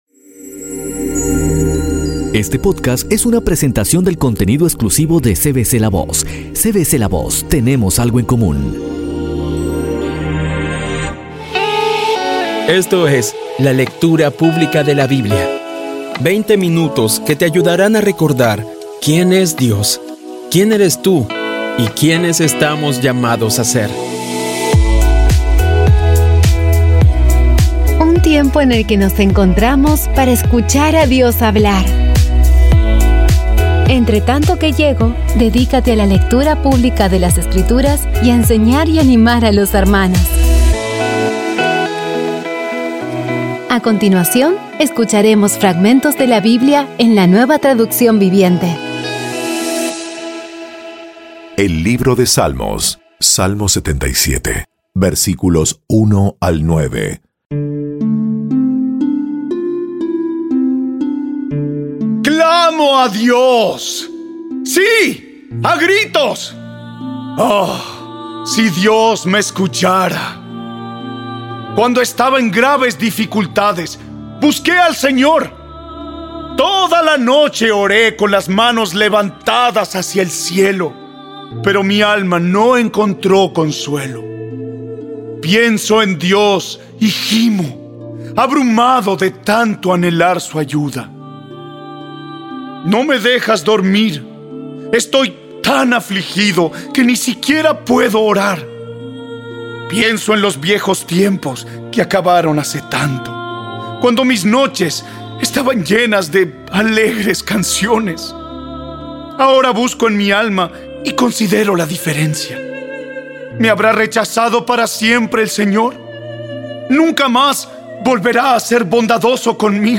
Audio Biblia Dramatizada por CVCLAVOZ / Audio Biblia Dramatizada Episodio 179